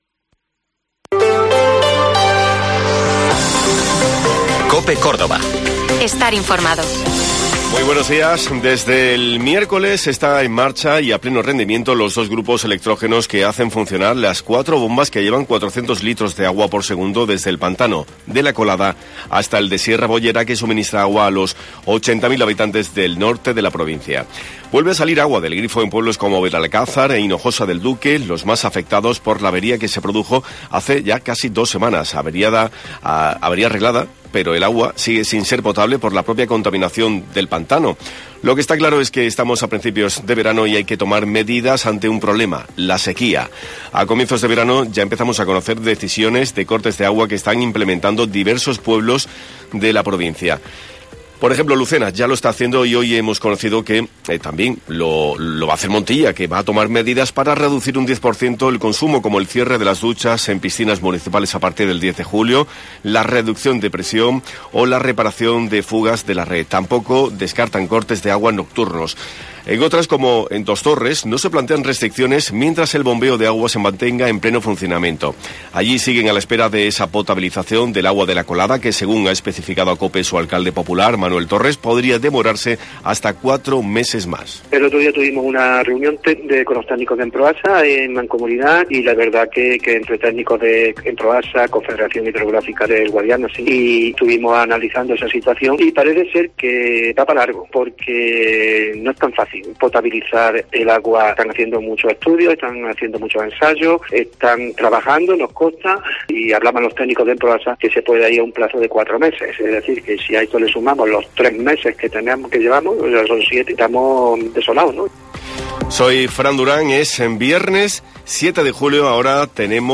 Informativo Herrera en COPE Córdoba